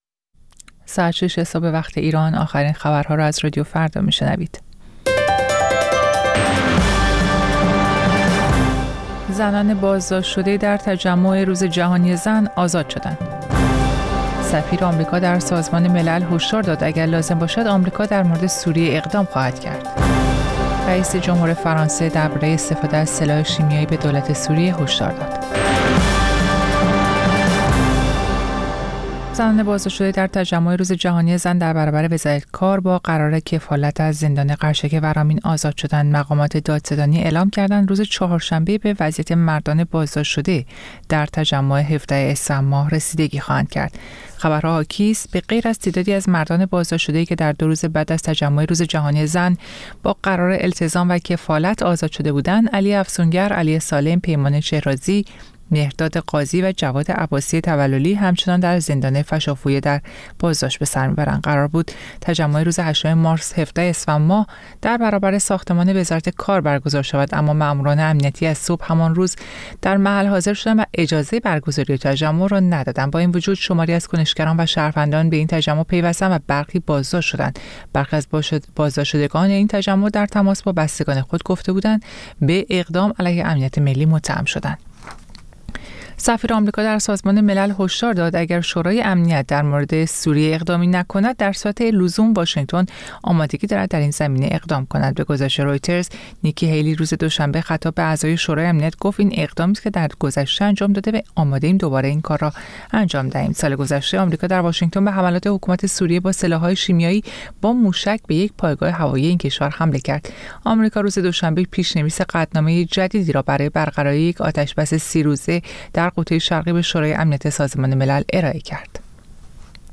سرخط خبرها